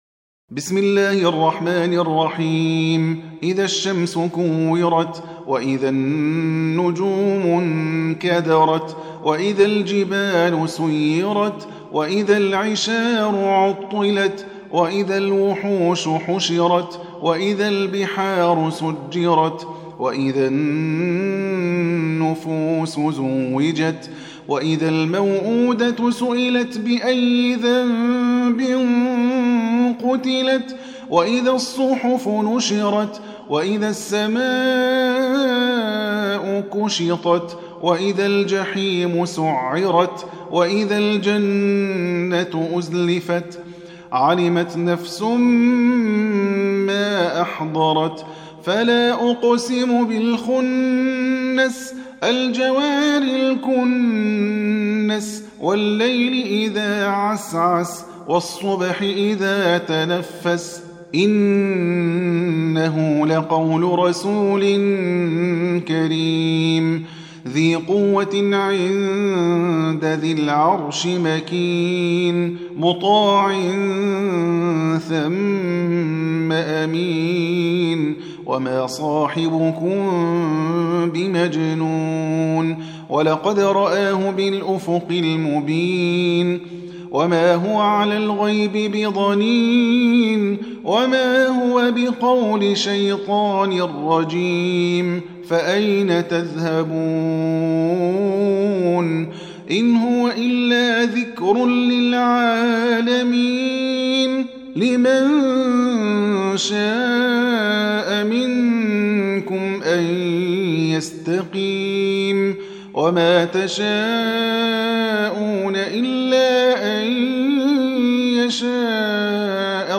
81. Surah At-Takw�r سورة التكوير Audio Quran Tarteel Recitation
Surah Repeating تكرار السورة Download Surah حمّل السورة Reciting Murattalah Audio for 81.